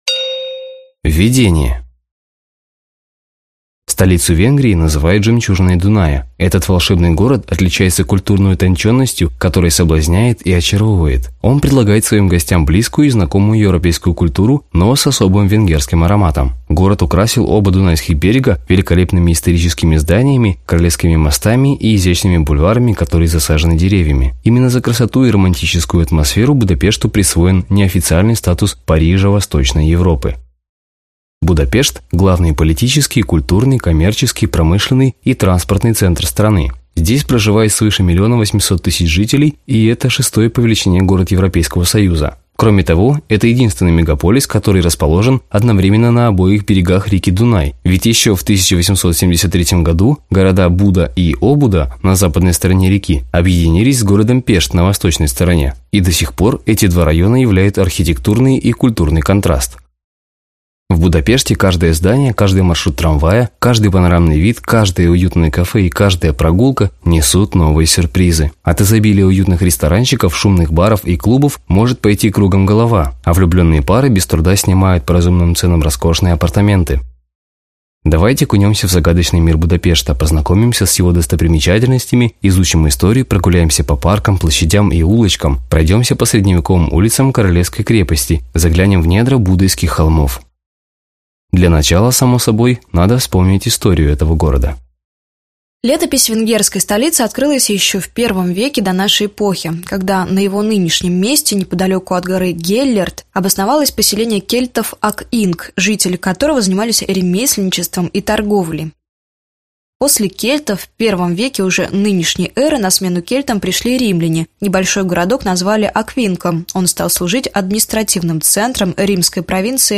Аудиокнига Будапешт: Прогулка по Пешту.